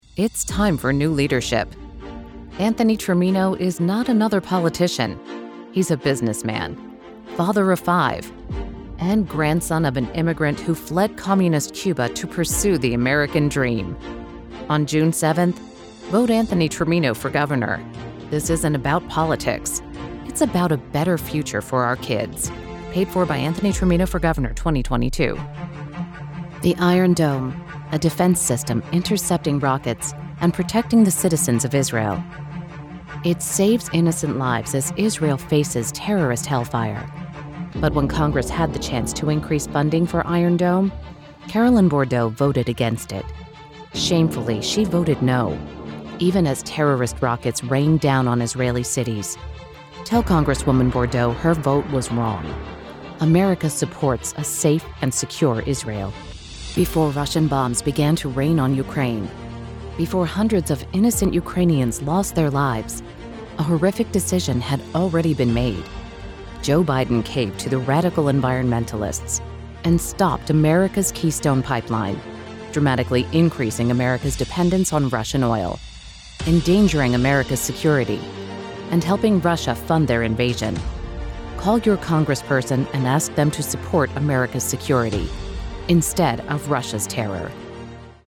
Female
Yng Adult (18-29), Adult (30-50)
Republican Spots
Words that describe my voice are Conversational, Sophisticated, Real.
All our voice actors have professional broadcast quality recording studios.